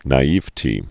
(nī-ēvtē, nä-, nī-ēvĭ-tē, nä-)